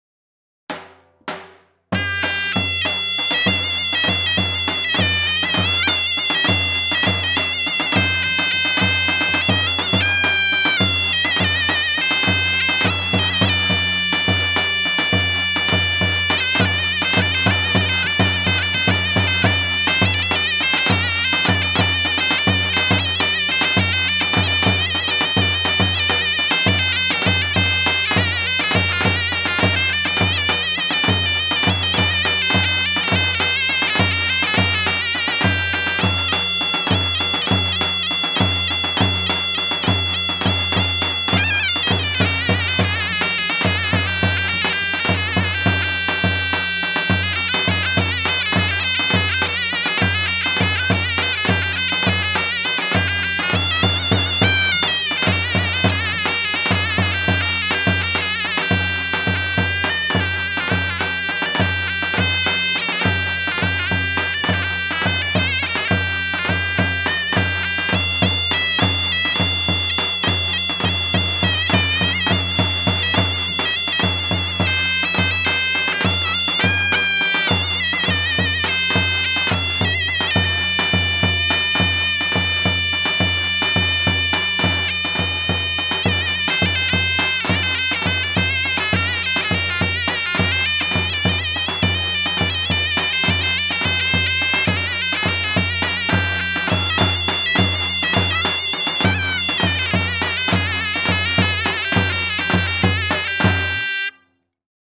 Sözlü, Sözsüz Yöresel Müzikler